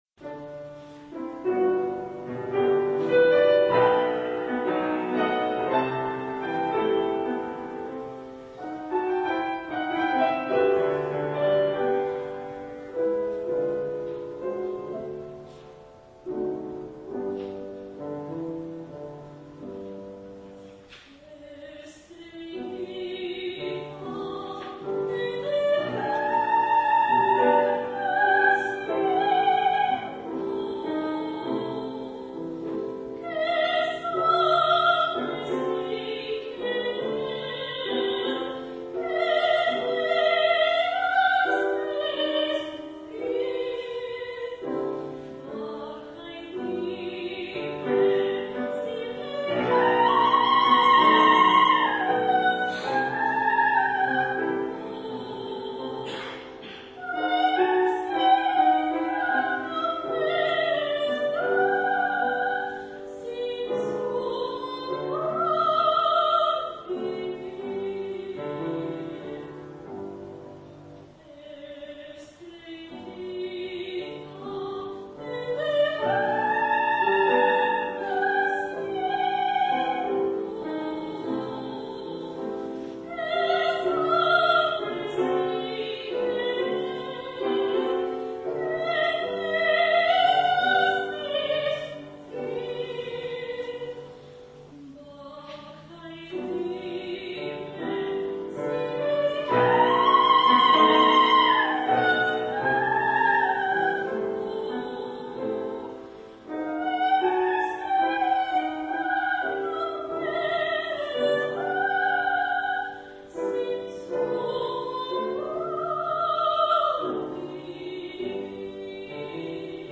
My voice type is coloratura soprano
pianist
And this one is a favorite, the Spanish song Estrellita by Manuel Ponce: